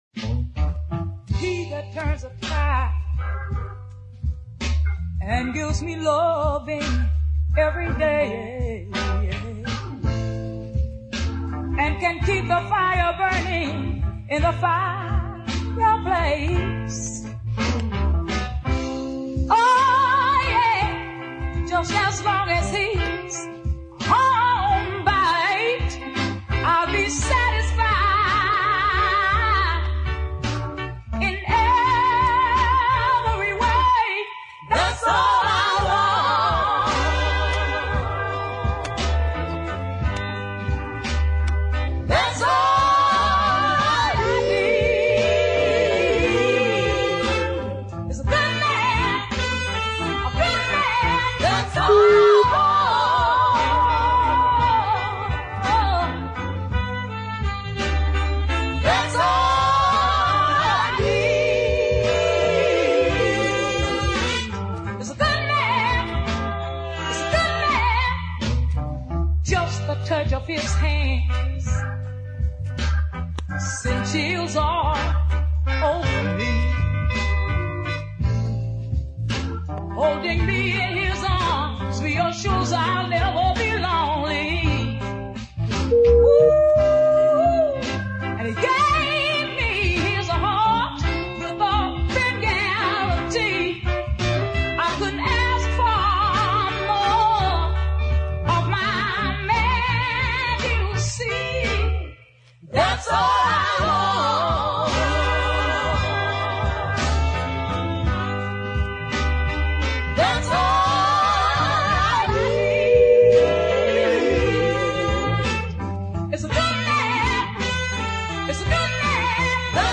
the bluesier side of soul music
fine deep ballad
a righteous group of female background singers